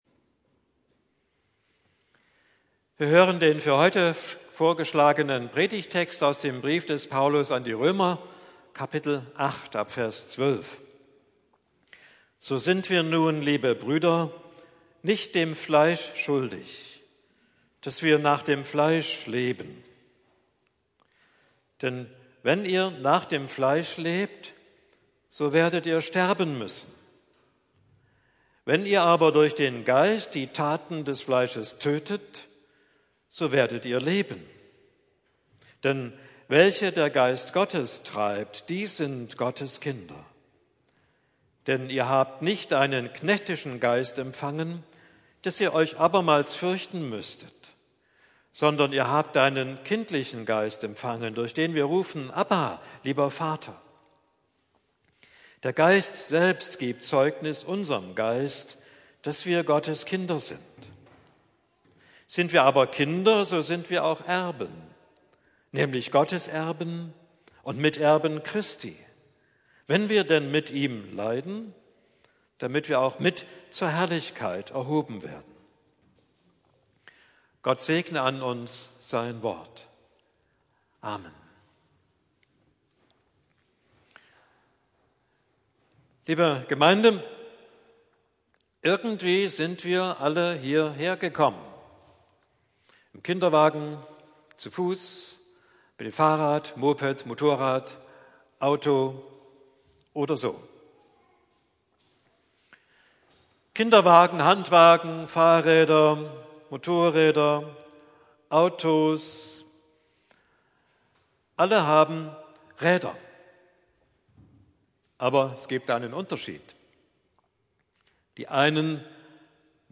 Fleisch oder Geist? Predigt im Gottesdienst